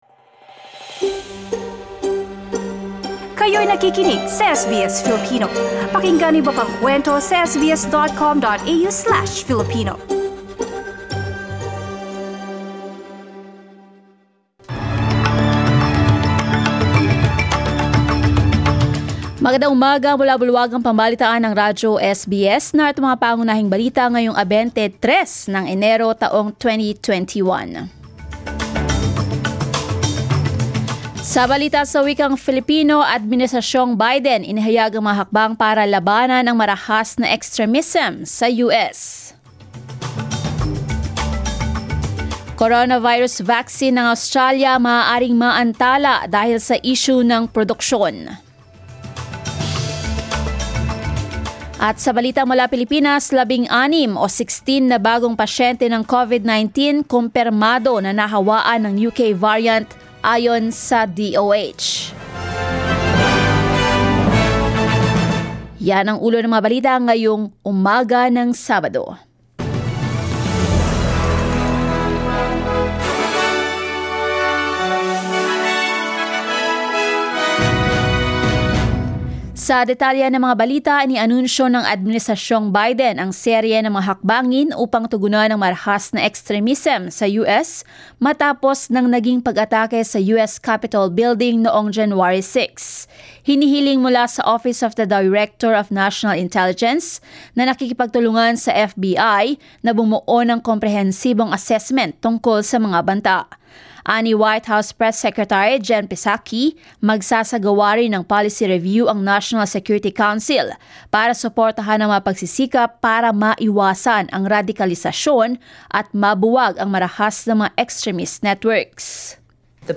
SBS News in Filipino, Saturday 22 January